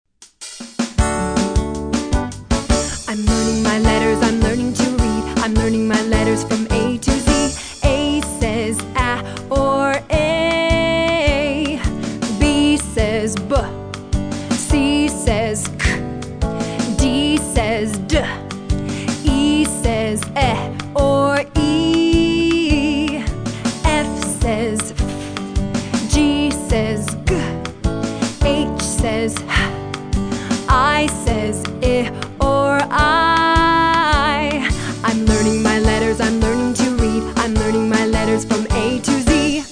Listen to a sample of this song.